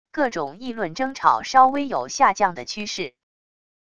各种议论争吵稍微有下降的趋势wav音频